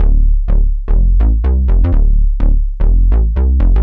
cch_bass_factory_125_Dm.wav